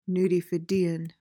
PRONUNCIATION:
(noo-dee-FID-ee-uhn)